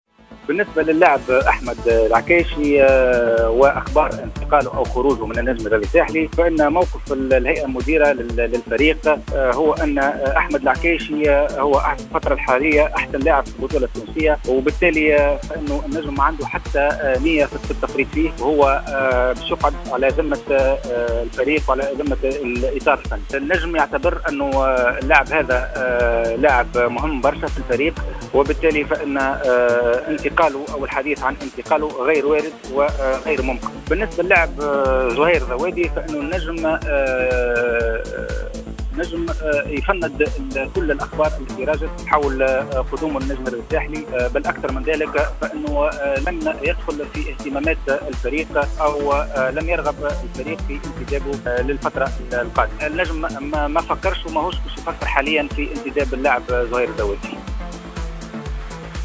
تصريح لجوهرة اف ام